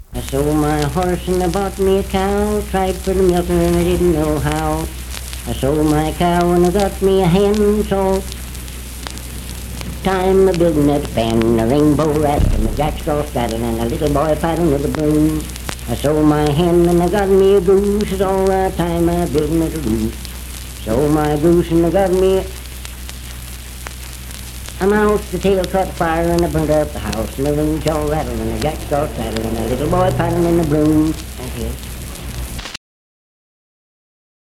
Unaccompanied vocal music
Performed in Sandyville, Jackson County, WV.
Dance, Game, and Party Songs, Humor and Nonsense
Voice (sung)